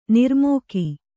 ♪ nirmōki